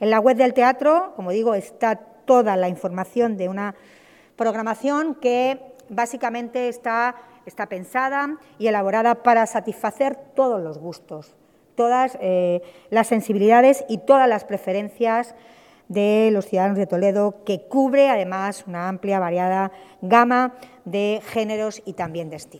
AUDIOS. Milagros Tolón, alcaldesa de Toledo